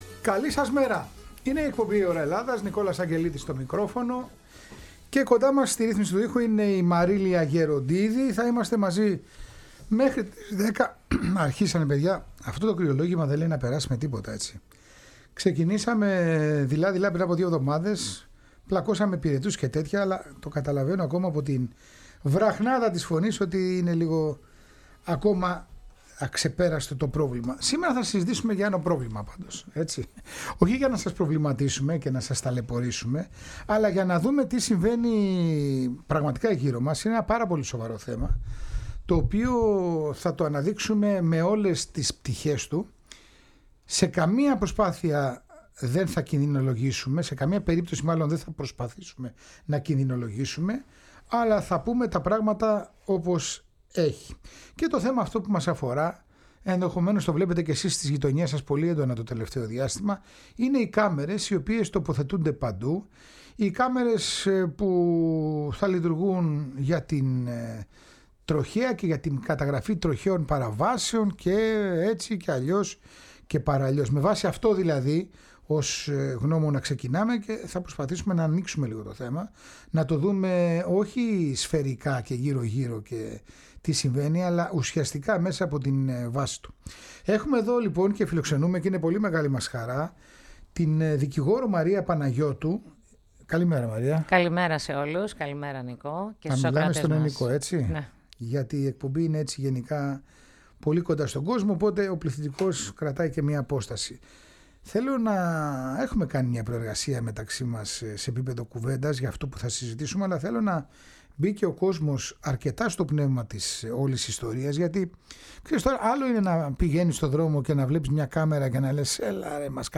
Μια σπουδαία κουβέντα, με πολλή ενημέρωση και απαντήσεις στα δικά σας ερωτήματα.
Συνεντεύξεις